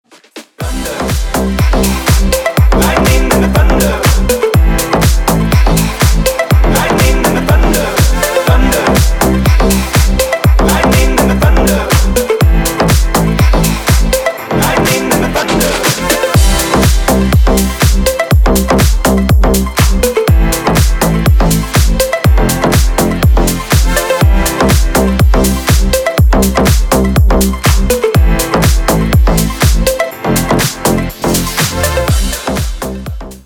• Качество: 320, Stereo
deep house
dance
Club House